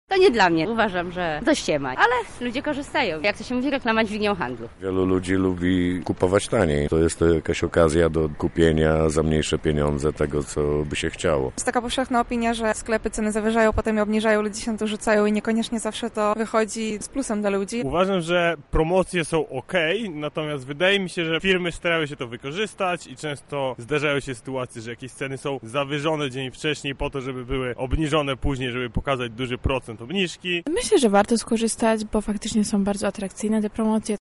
Zapytaliśmy klientów jednego z lubelskich centrów handlowych, co uważają o tym dniu:
SONDA